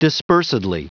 Prononciation du mot dispersedly en anglais (fichier audio)
Prononciation du mot : dispersedly